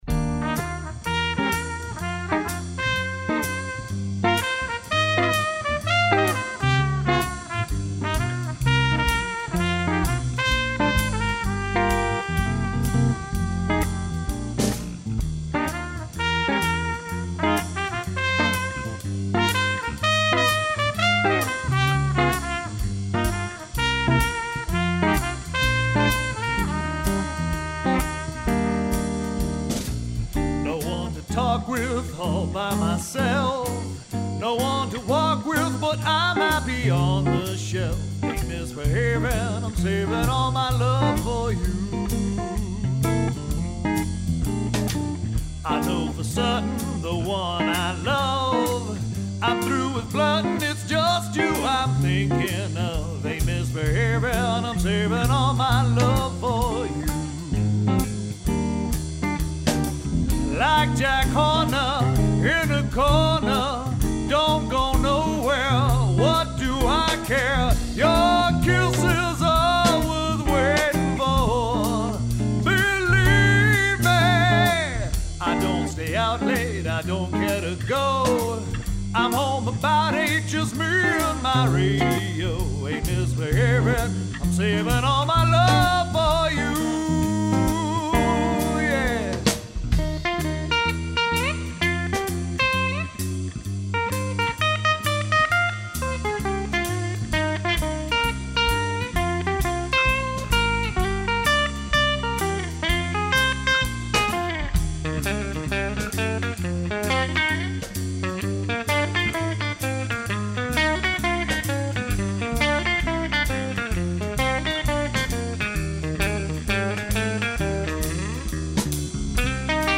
Blues, Soul & Jazz Duo
Ain't Misbehavin' - jazz standard